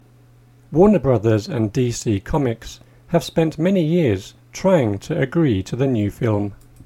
DICTATION 7